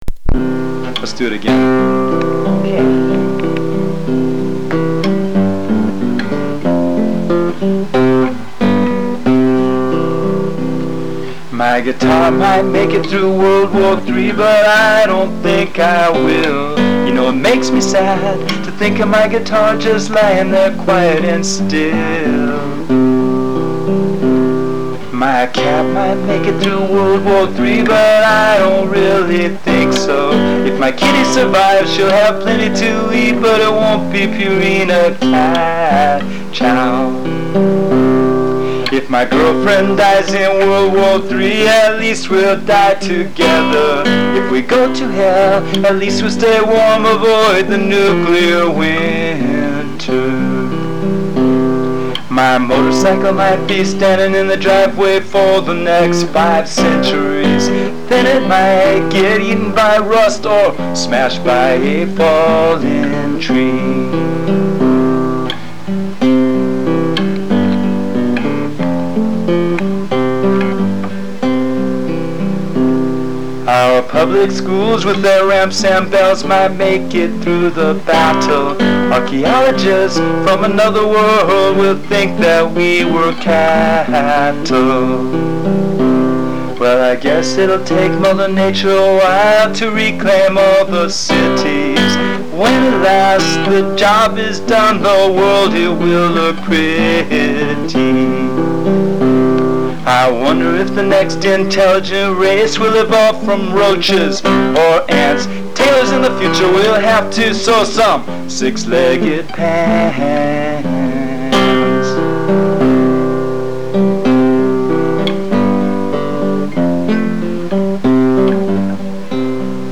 Here's a song I wrote about the situation you describe when I was a young man, recorded decades later when I was 50 from the depths of my memory on an old cassette recorder with my then 5-year-old daughter holding a $2 Radio Shack mic.